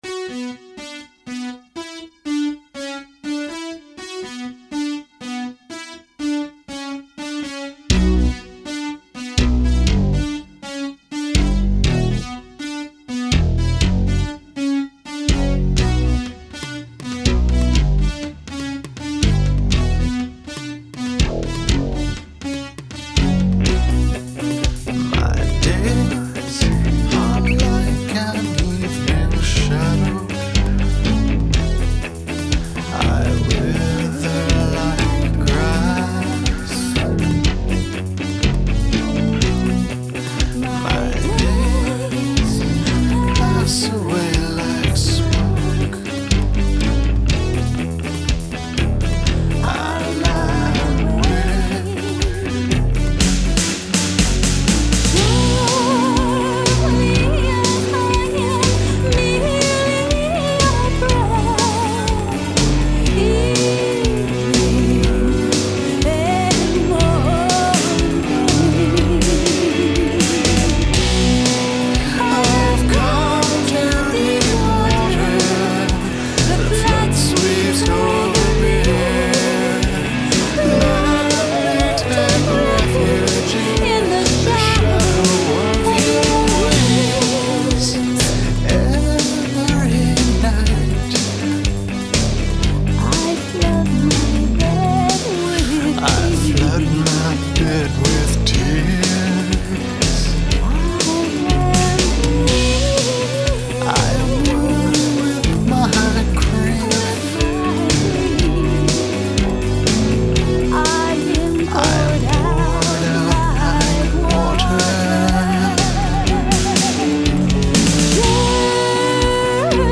It was recorded and mixed at home.